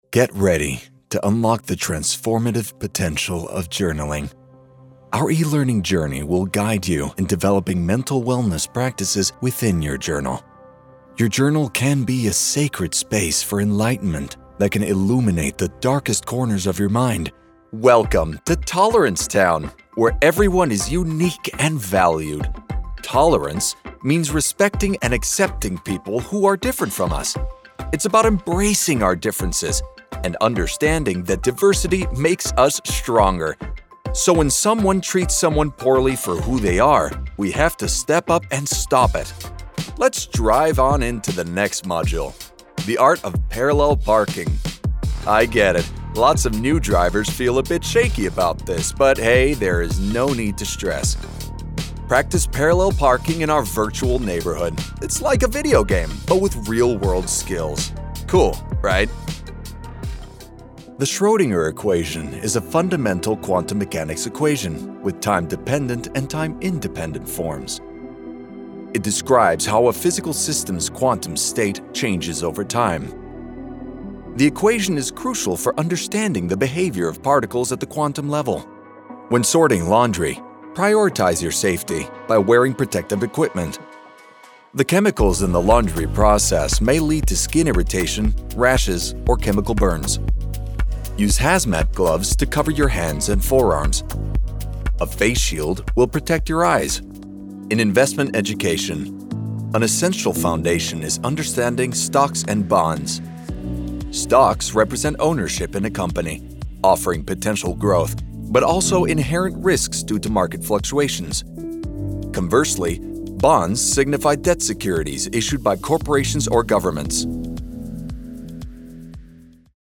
eLearning Reel
General American, Spanish, Southern USA, New Orleans
Young Adult